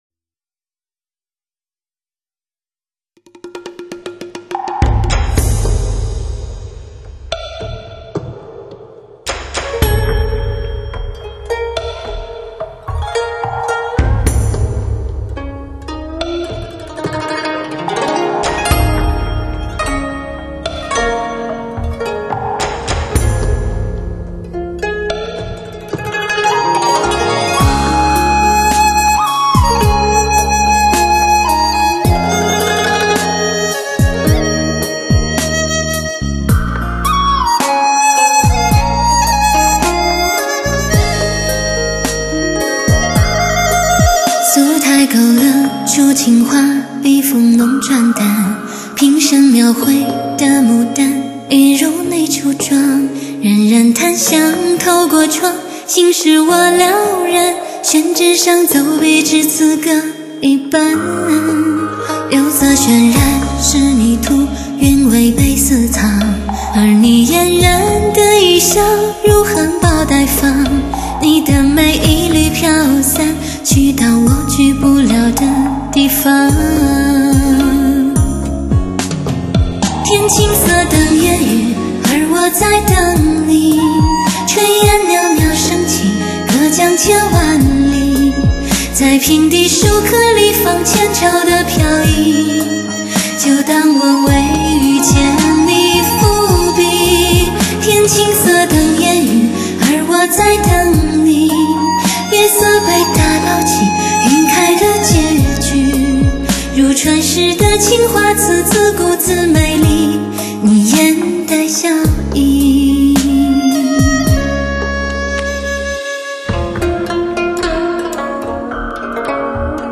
旋律轻柔流畅  器乐创新搭配  节奏舒缓时尚